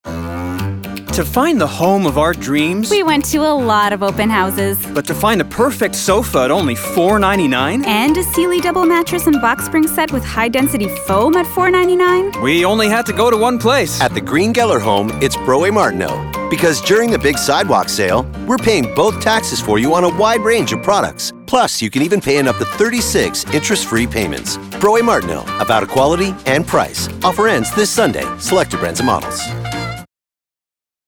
Publicité (Brault & Martineau) - ANG